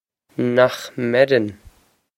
Pronunciation for how to say
Nokh mer-on?
This is an approximate phonetic pronunciation of the phrase.